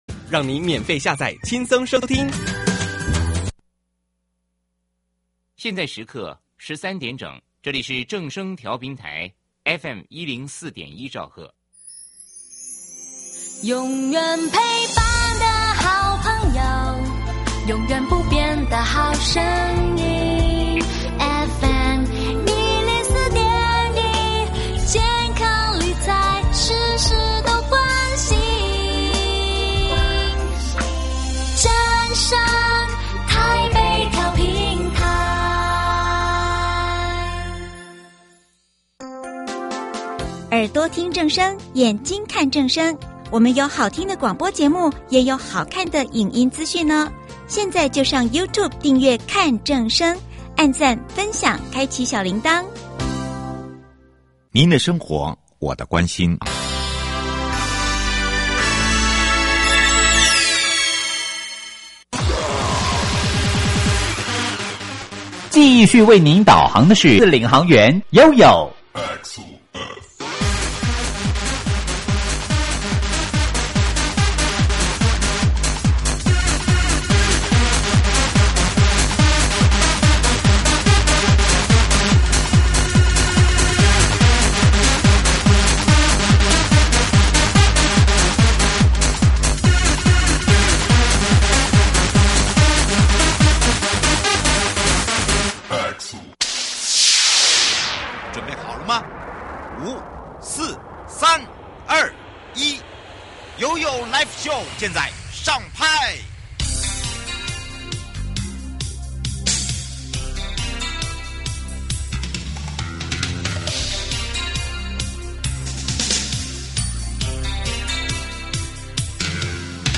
受訪者： 營建你我他 快樂平安行~七嘴八舌講清楚~樂活街道自在同行!(一) 六都人行環境評鑑 台北市拿下三項第